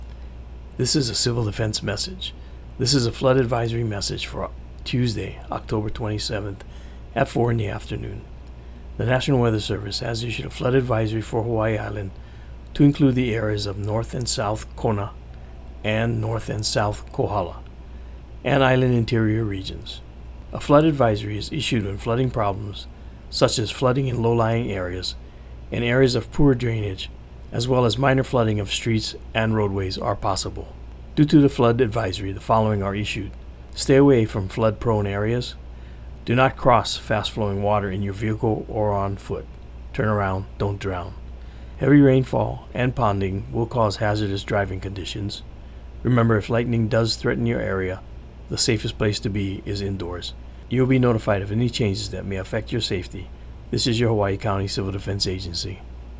Hawaiʻi County Civil Defense produced this radio message after the advisory was issued: